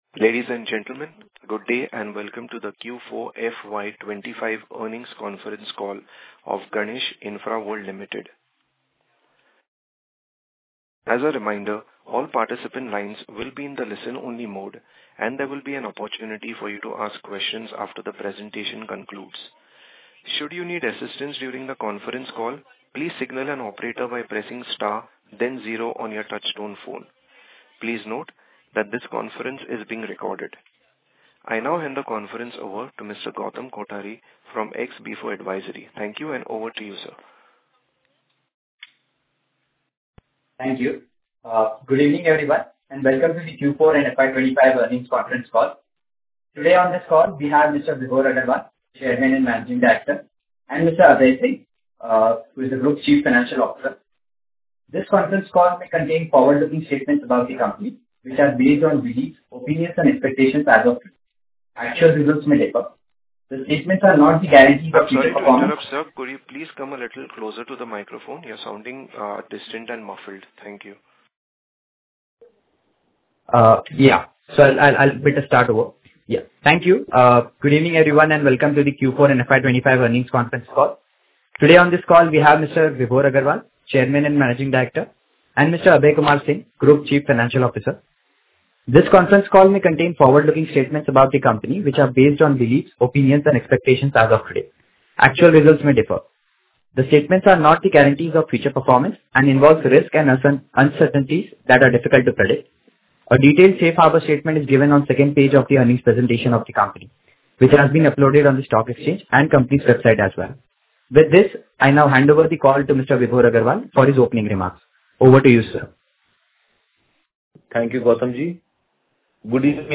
Concalls
GIL-Q4FY25-EarningsConcallRecording.mp3